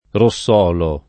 rossolo [ ro SS0 lo ]